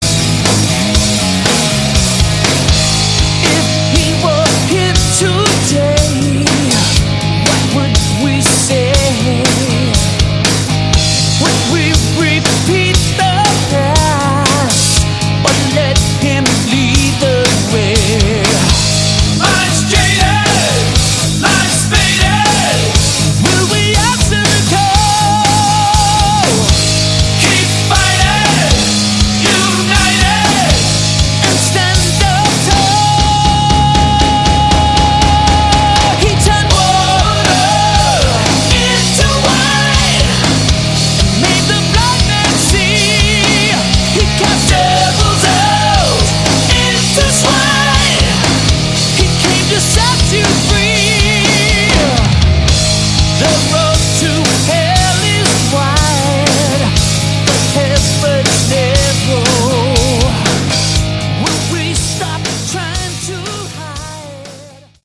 Category: CCM Hard Rock